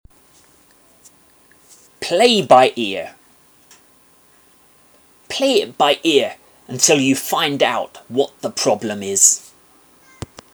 マンツーマンのレッスン担当の英語ネイティブによる発音は下記のリンクをクリックしてください。